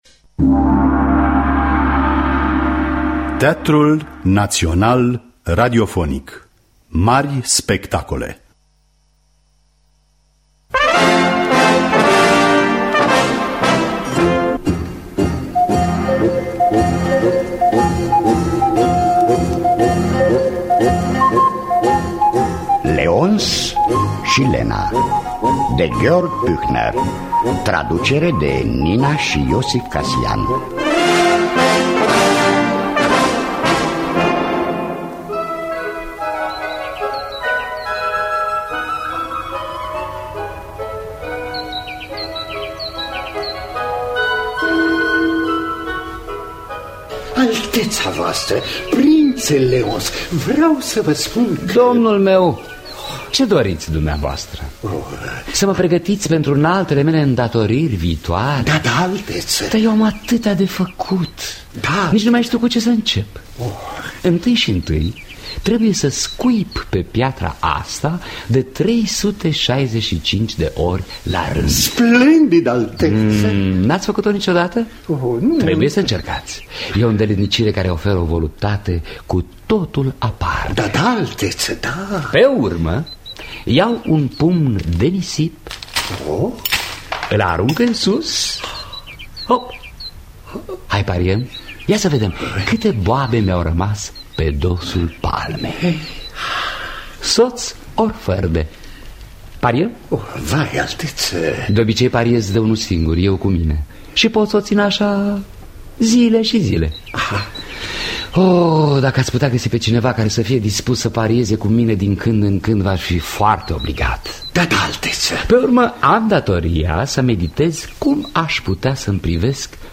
Adaptarea radiofonică de Adrian Pintea.